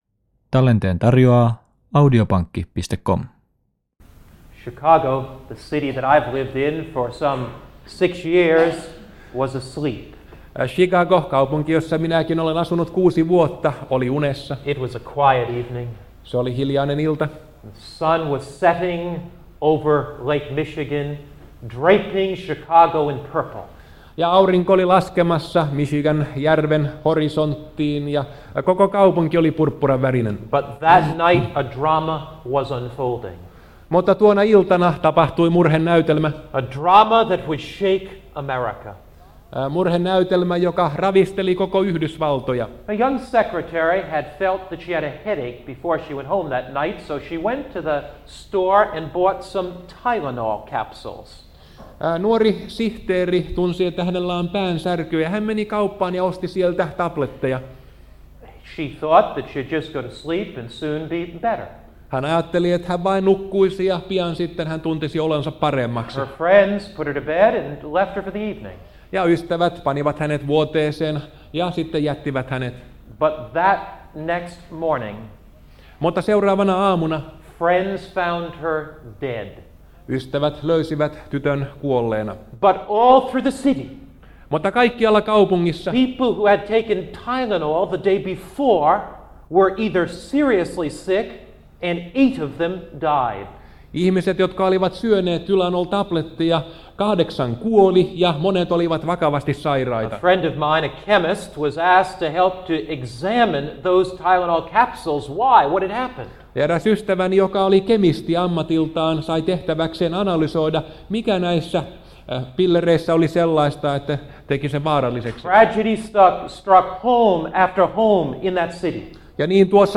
Helsingissä 1980-luvulla